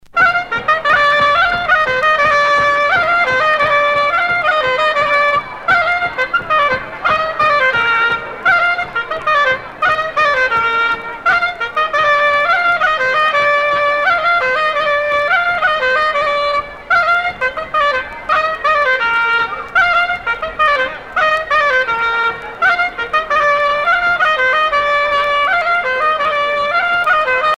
danse : plinn
Sonneurs de clarinette
Pièce musicale éditée